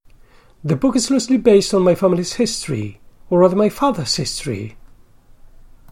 In none of the above sentences, the last accent is placed on the last lexical word but on the immediately previous one.